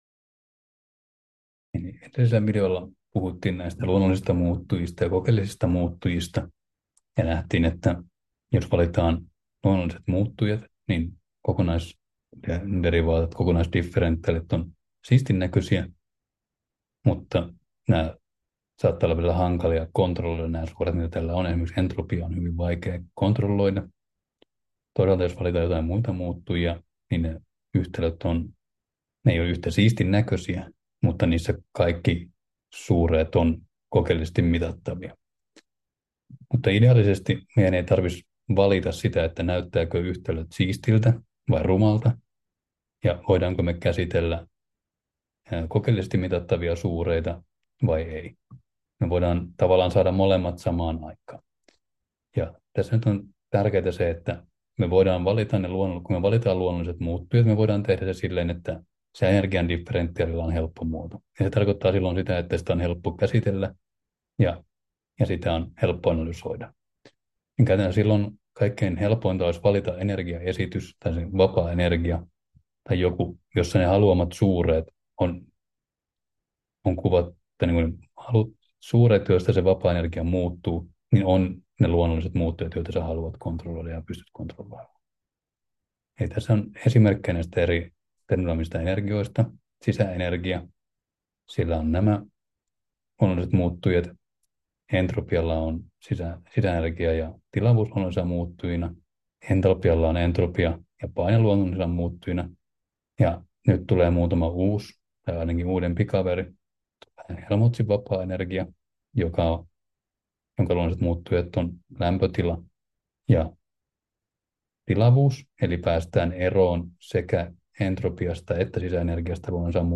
Luento 6: Vapaa energia 5 — Moniviestin